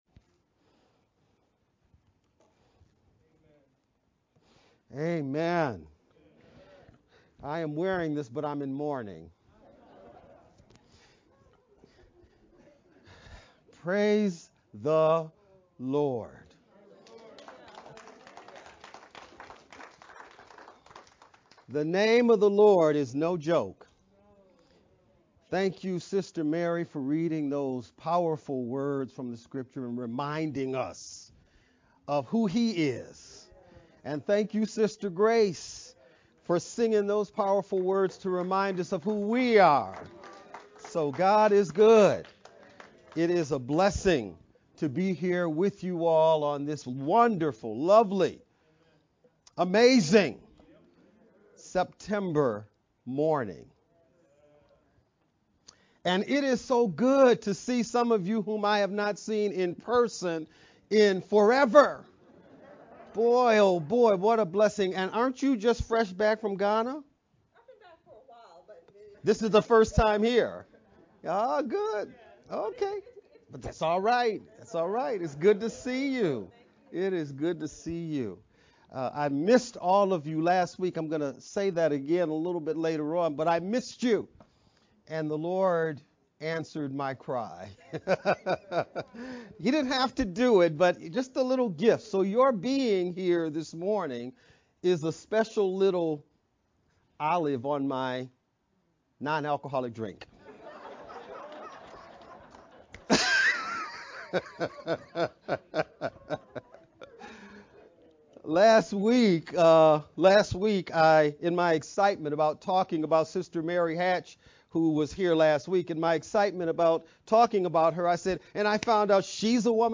VBCC-Sept-18th-sermon-edited-sermon-only-Mp3-CD.mp3